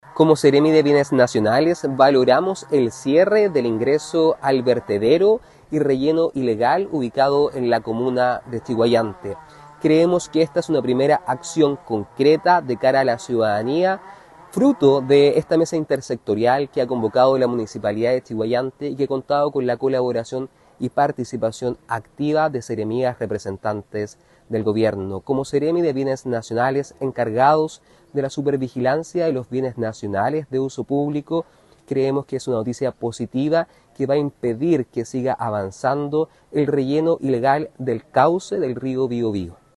En tanto, el seremi de Bienes Nacionales, Sebastián Artiaga, valoró el trabajo coordinado y explicó que están “encargados de la supervigilancia de los bienes nacionales de uso público”.